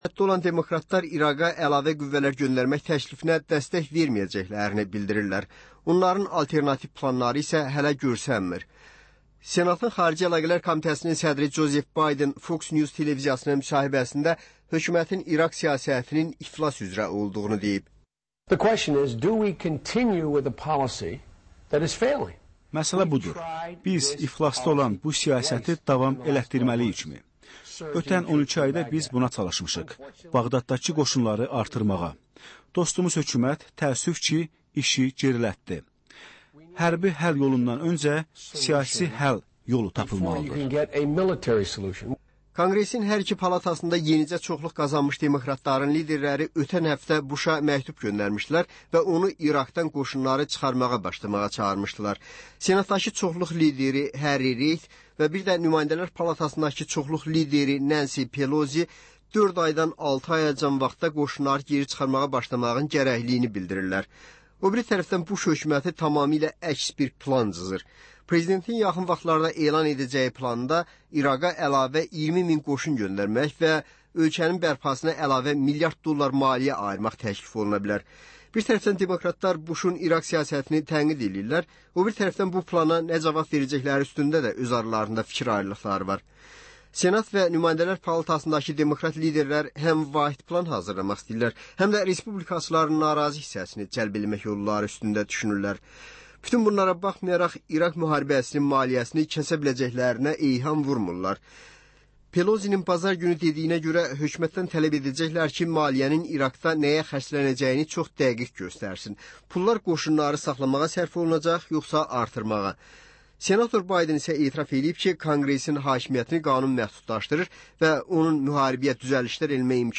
Azərbaycan Şəkilləri: Rayonlardan reportajlar.